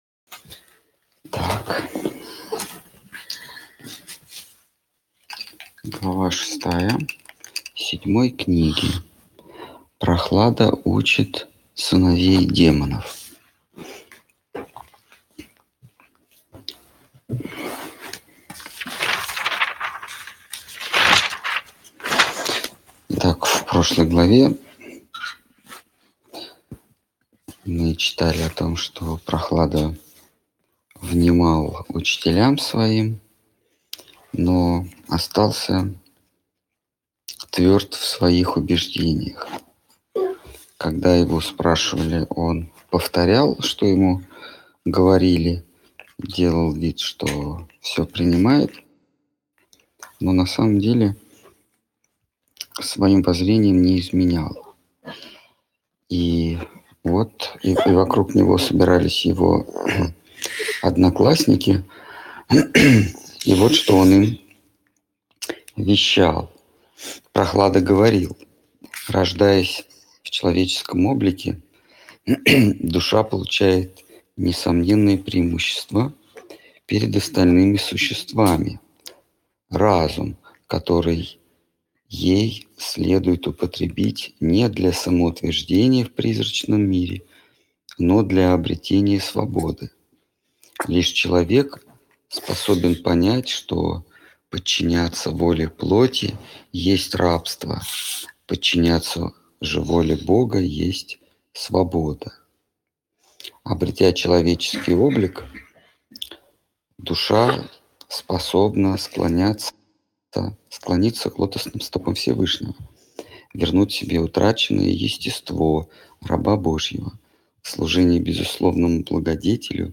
Ответы на вопросы из трансляции в телеграм канале «Колесница Джаганнатха». Тема трансляции: Шримад Бхагаватам.